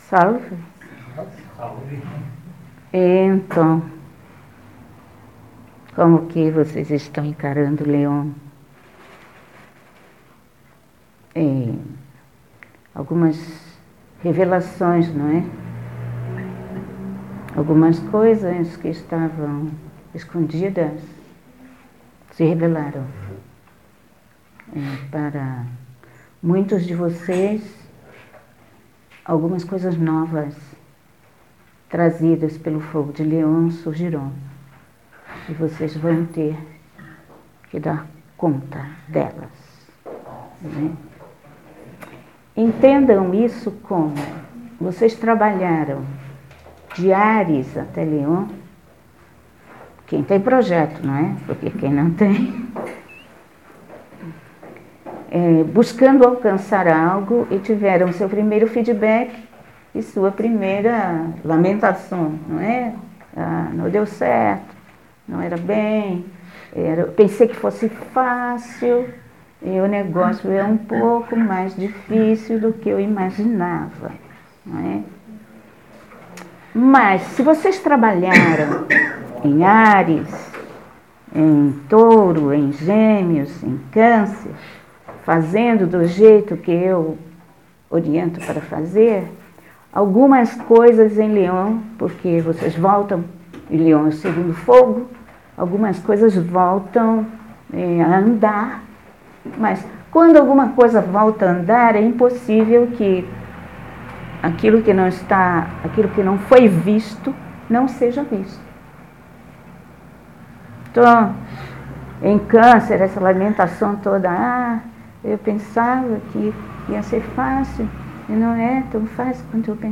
Perguntas e respostas